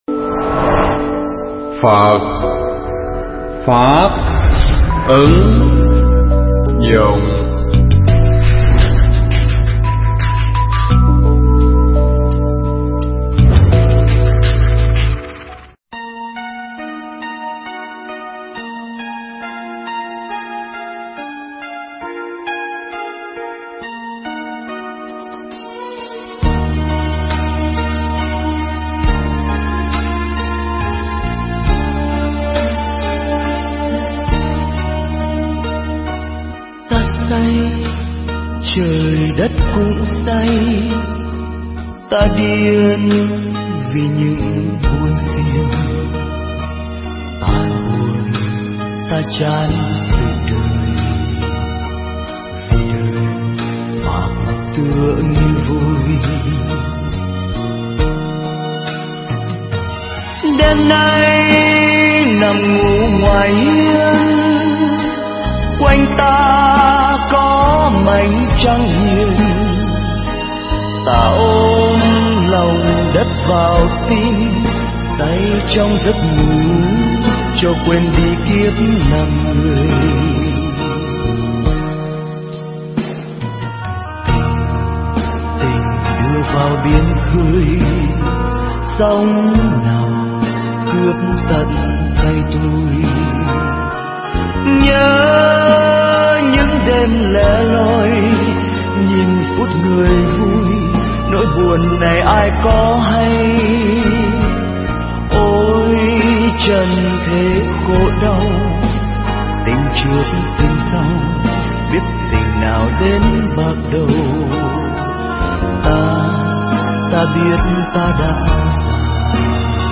Nghe mp3 thuyết pháp Không Rượu Mà Say